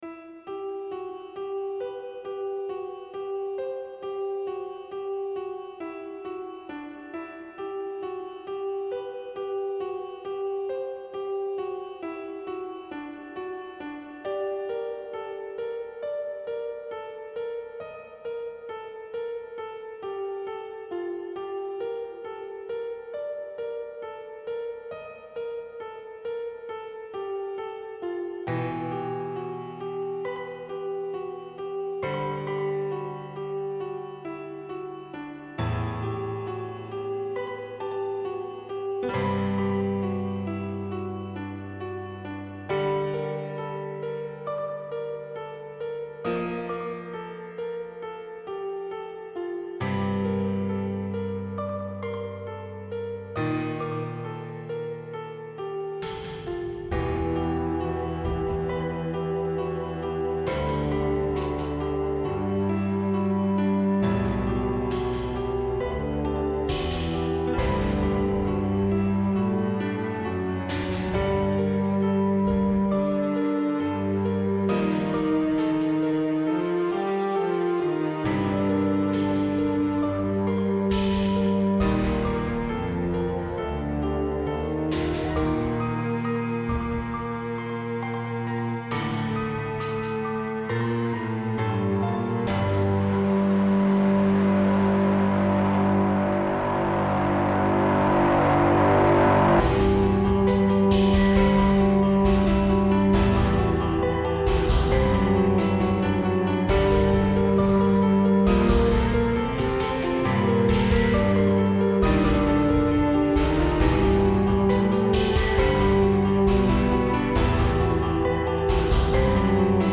Mes compos rock/metal instrumental : écoutez voir!
Pour info, c'est enregistré/mixé sous Tracktion.
Batterie : Samples du NSKit (gratuit) dans le sampler basique de Tracktion.
Piano : Soundfont gratuite dans SFX